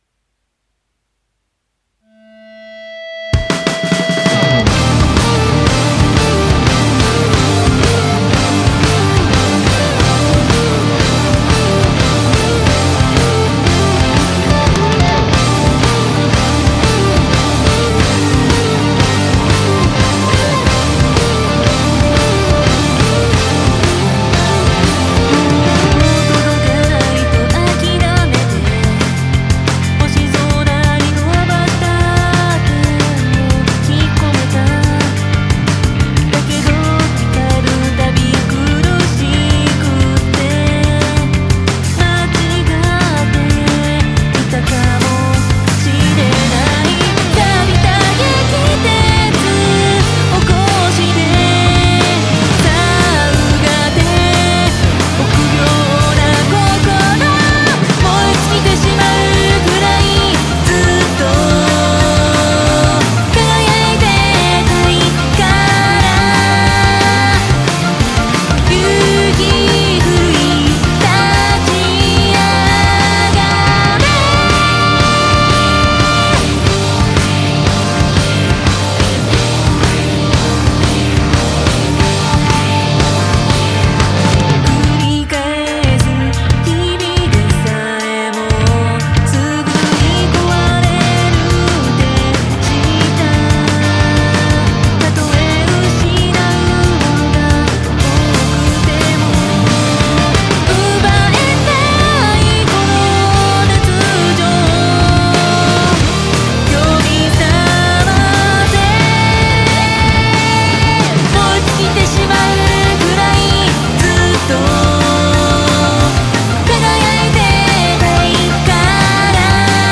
ロック系アニソン、ボカロのカバーを中心に鳥取島根で活動。